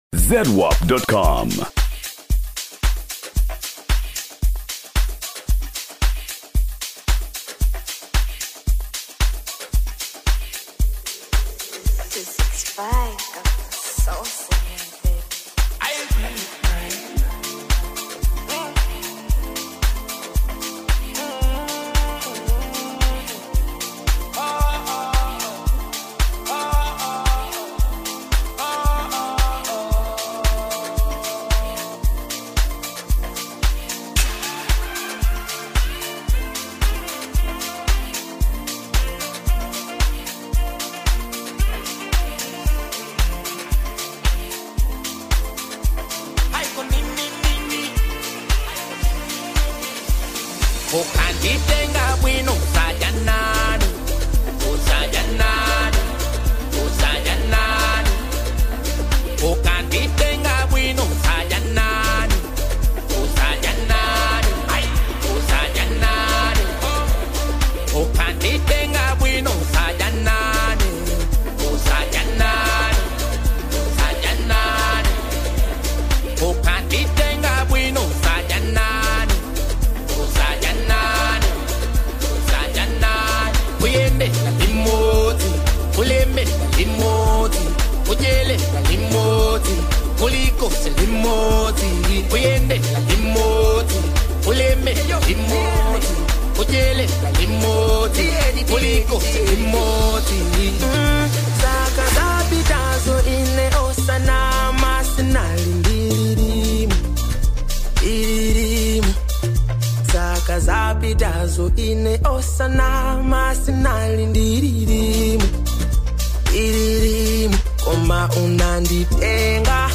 Latest Malawi Afro-Beats Single (2026)
Genre: Afro-Beats
blending catchy melodies with meaningful songwriting.